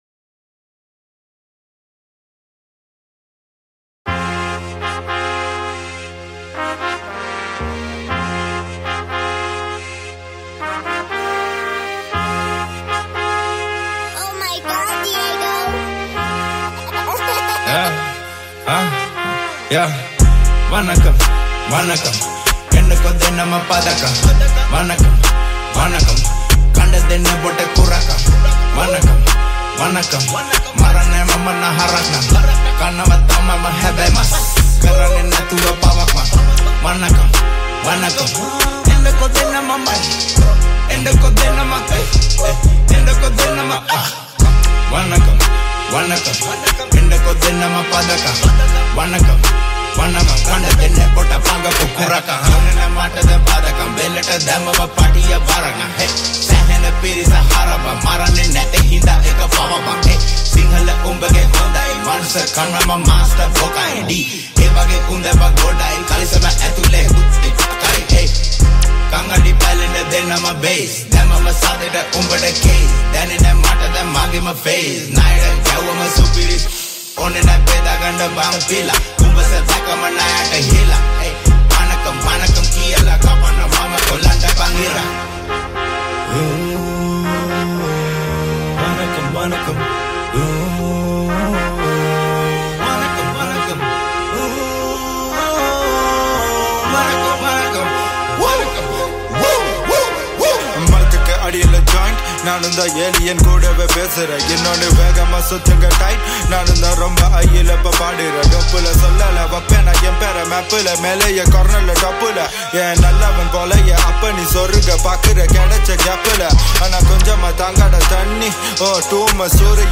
Guitars
Trumpet